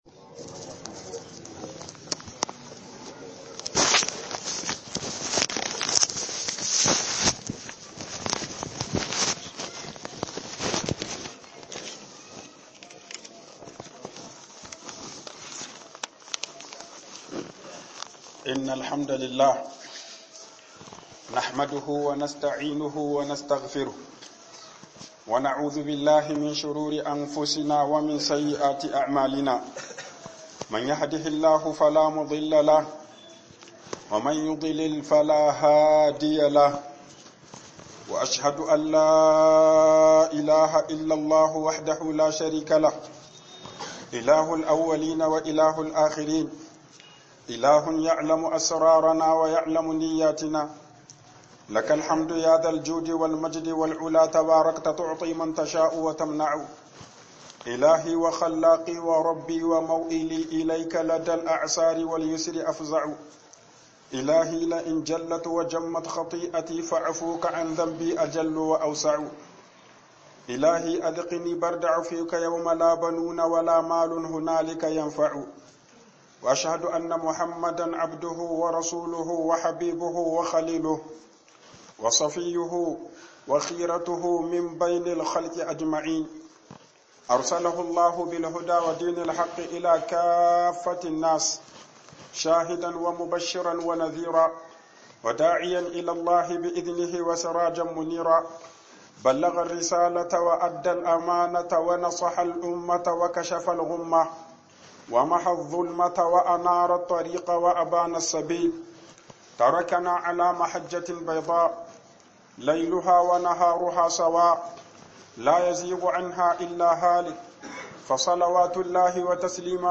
Aikin Likita Ba Shagon Neman Kudi Bane 01 - HUƊUBOBIN JUMA'A